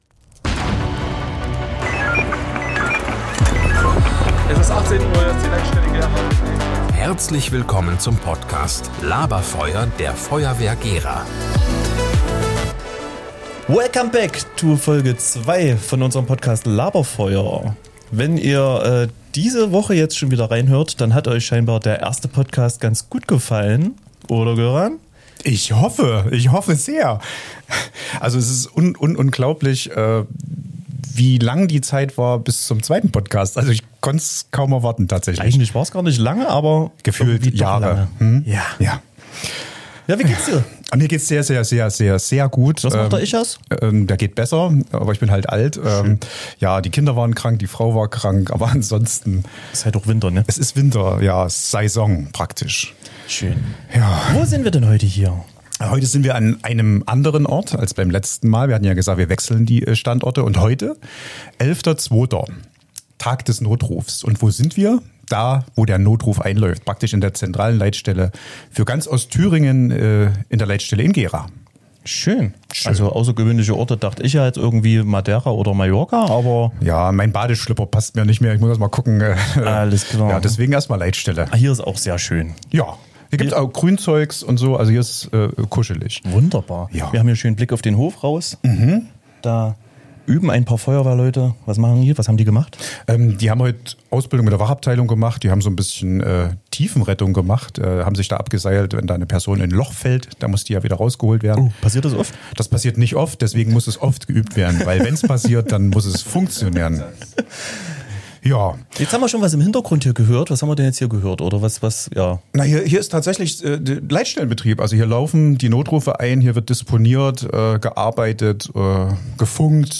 Er nimmt uns mit hinter die Kulissen des Notrufs – dorthin, wo Sekunden zählen und Entscheidungen Leben retten. Im Nebenraum der Leitstelle Gera, mit sogenannten Ausnahmeabfrageplätzen, haben wir zum Tag des Notrufs die 2. Folge Laber-Feuer für euch brandheiß aufgenommen.